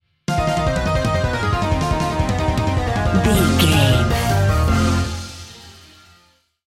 Aeolian/Minor
aggressive
driving
energetic
frantic
bass guitar
synthesiser
percussion
electric piano